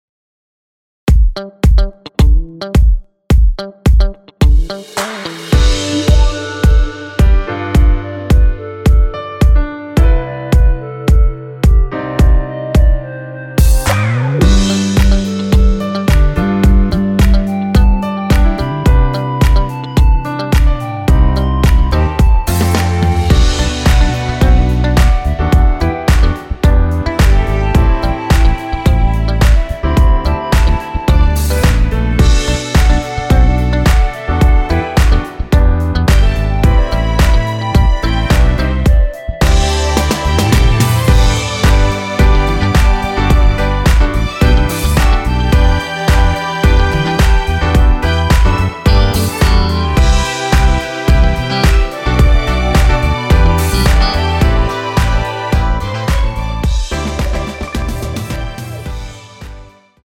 원키에서(+3)올린 멜로디 포함된 MR입니다.(미리듣기 확인)
앞부분30초, 뒷부분30초씩 편집해서 올려 드리고 있습니다.
중간에 음이 끈어지고 다시 나오는 이유는